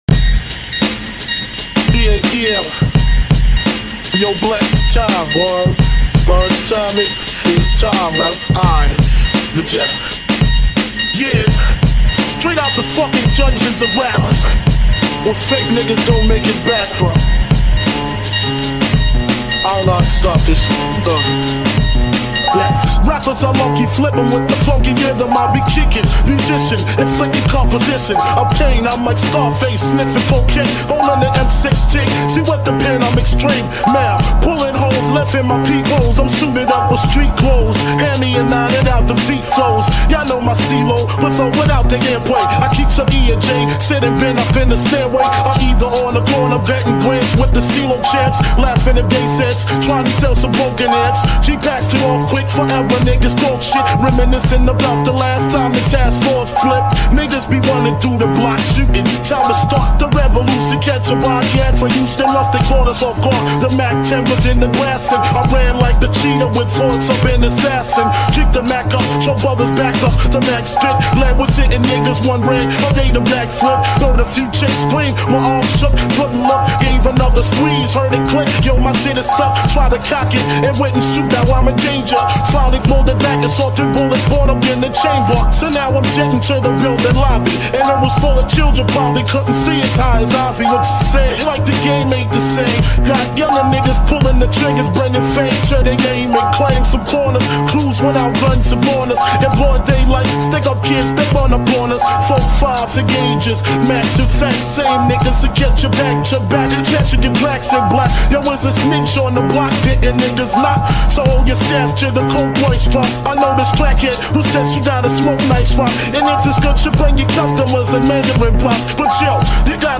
(A.I. COVER)